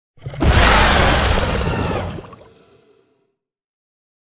Sfx_creature_squidshark_death_01.ogg